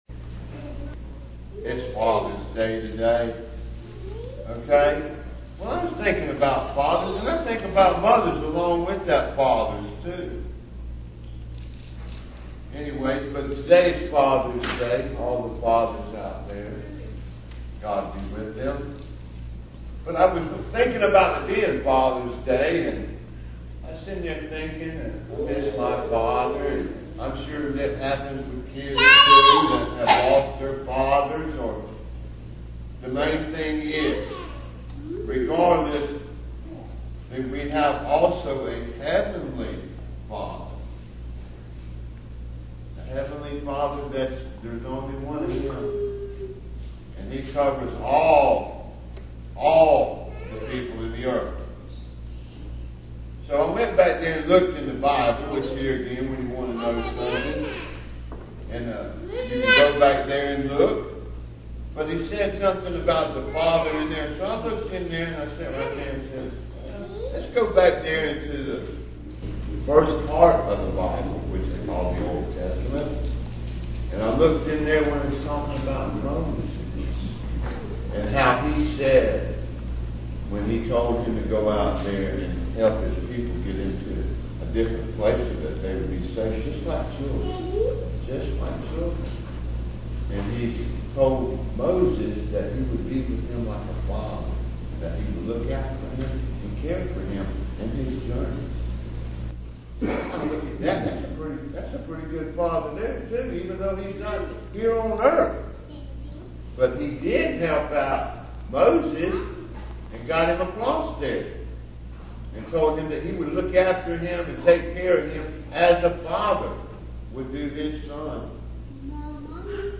Children's Sermons